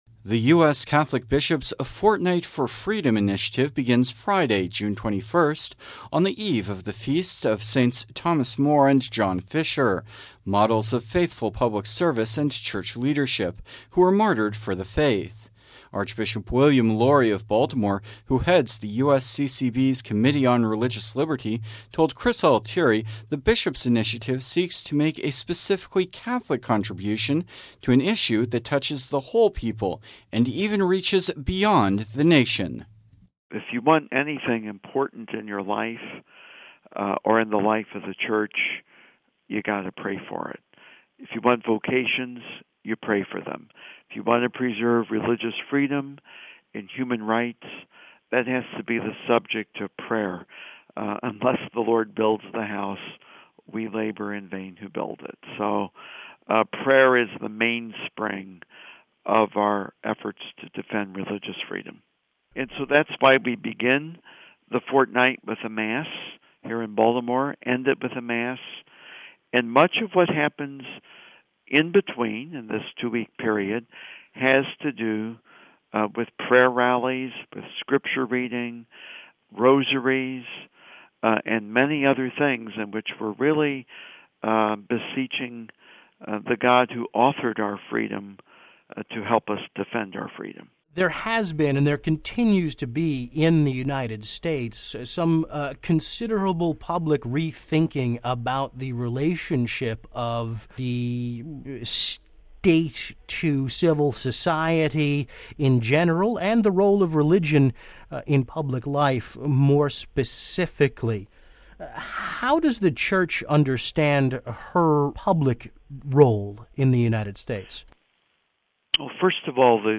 Archbishop William Lori of Baltimore, who heads the USCCB’s Committee on Religious Liberty, told Vatican Radio the bishops’ initiative seeks to make a specifically Catholic contribution to an issue that touches the whole people and even reaches beyond the nation.